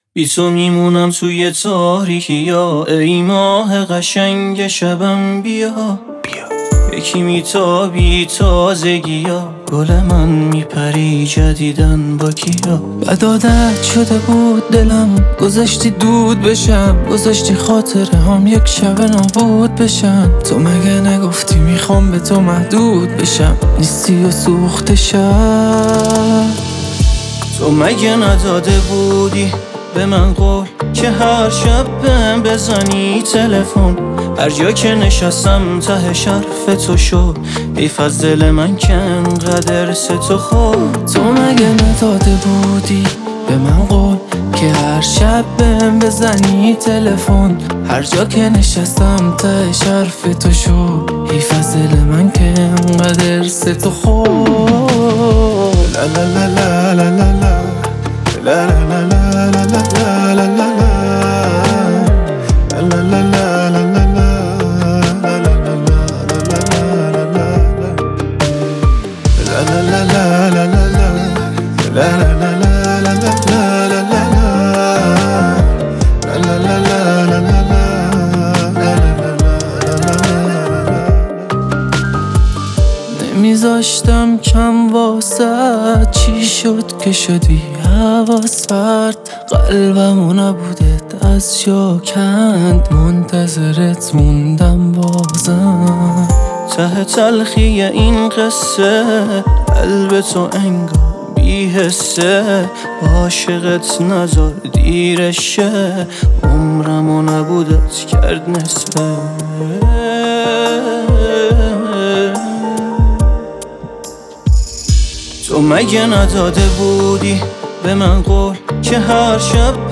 پاپ
آهنگ با صدای زن
اهنگ ایرانی